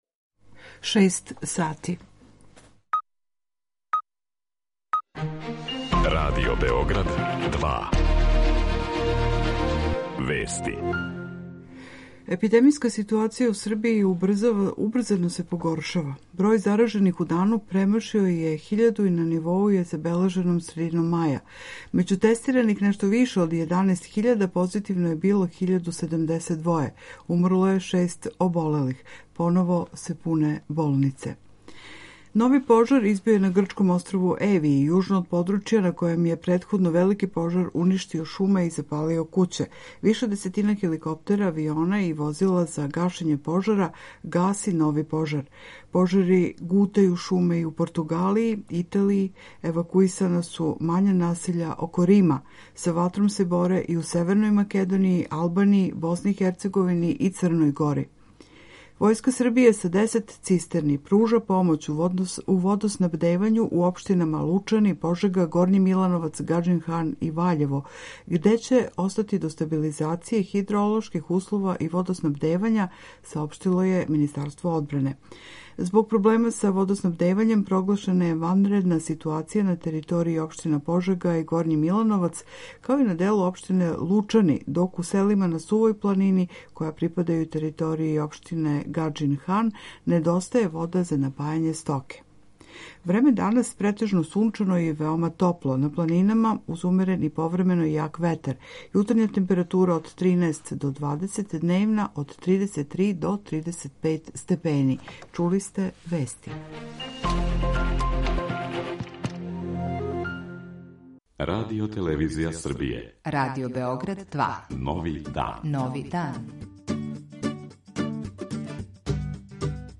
О књизи, као и о акцијама Каритаса, говоримо са господином Станиславом Хочеваром.